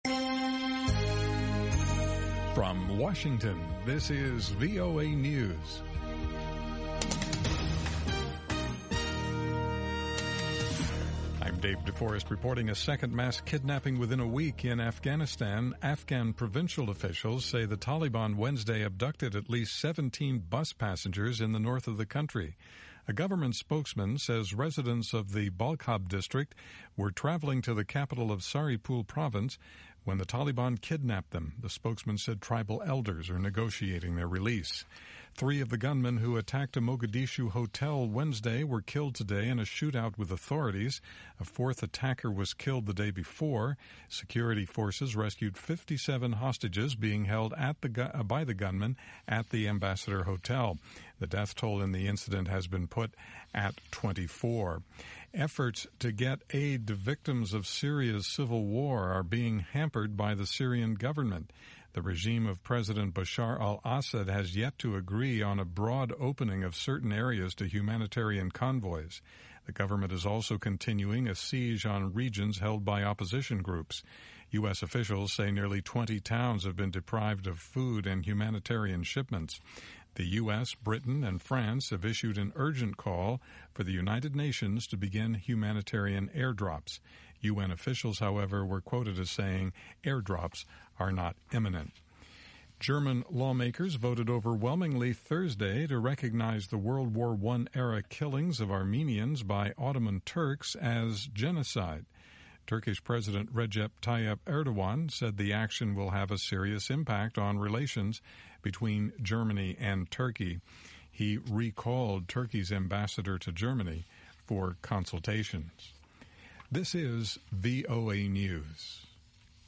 2000 UTC Hourly Newscast in English